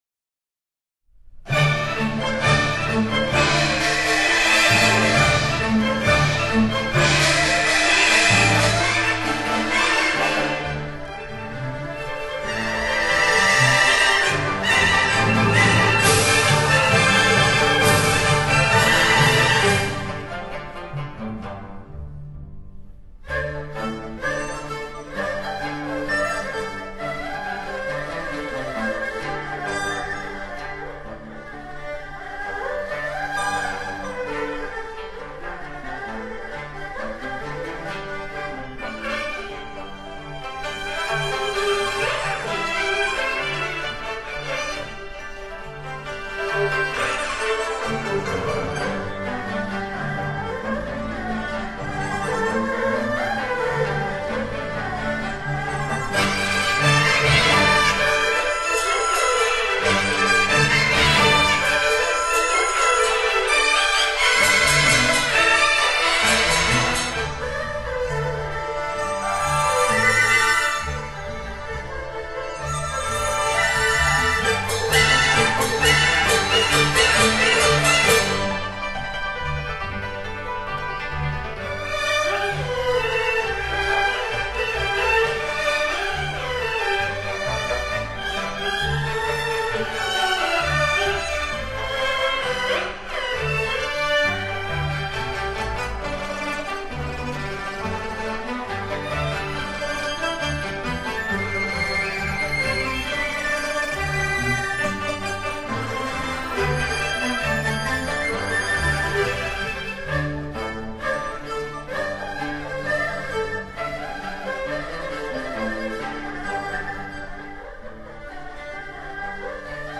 长城随想 民族交响乐